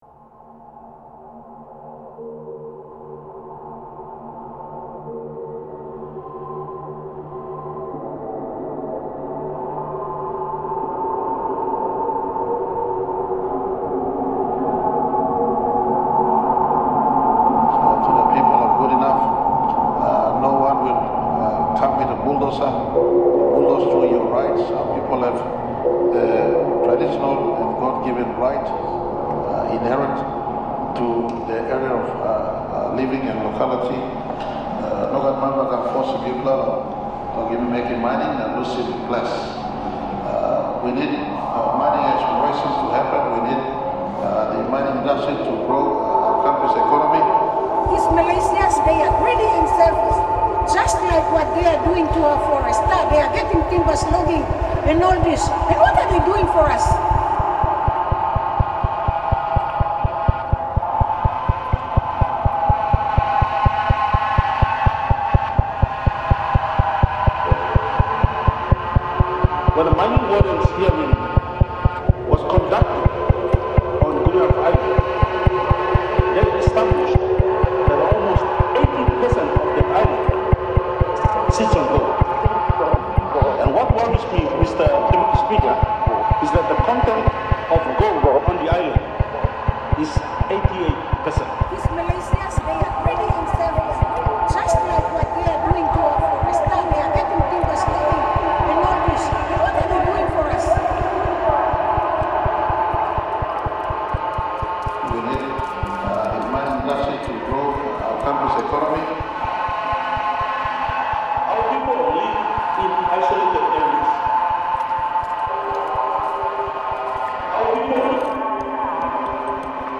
I was delighted because this randomness fits with my experimental approach to creating music.
it’s a dance song where singers are arranged in parallel rows